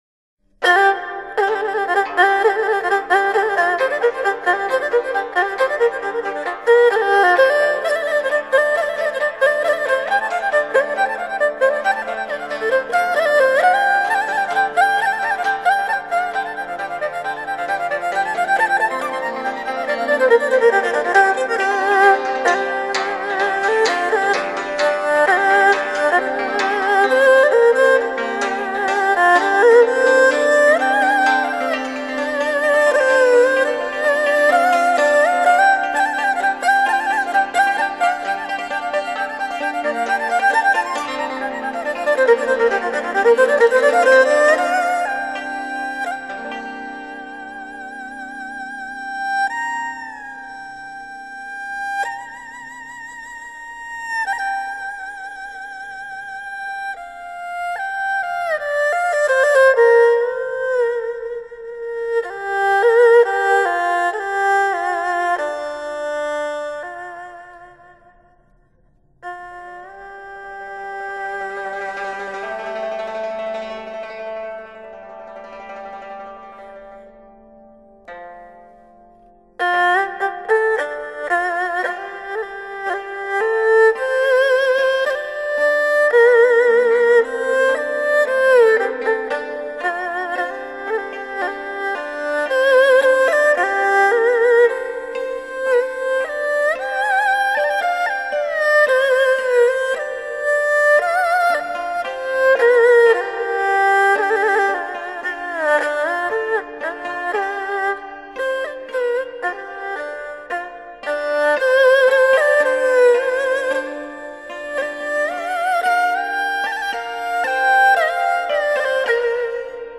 二胡演奏
乐曲通过欢快的旋律赞颂可爱的解放军。